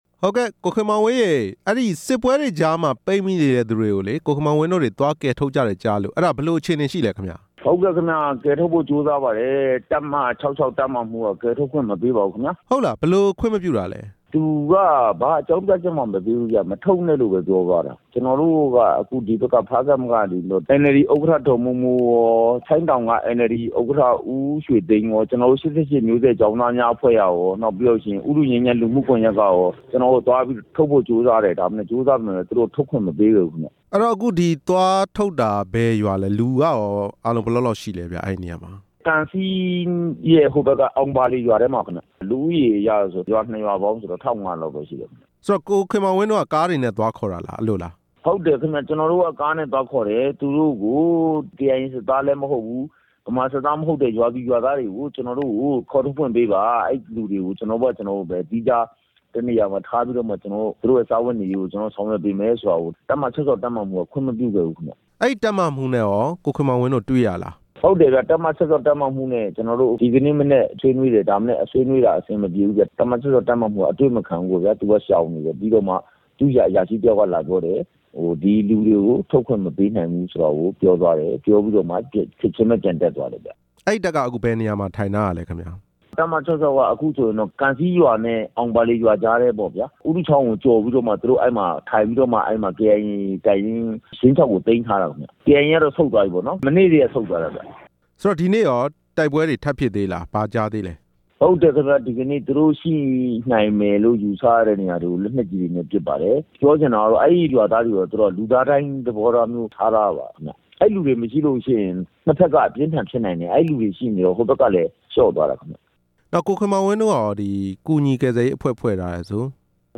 ကချင်ပြည်နယ်က တိုက်ပွဲတွေကြားထဲ ရွာသားတွေ ပိတ်မိနေတဲ့အကြောင်း မေးမြန်းချက်